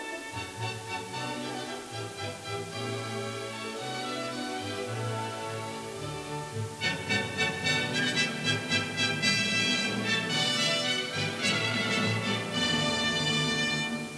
These are arranged in degrading quality.
Yet most 8-bit files sound terrible with lots of static & noise.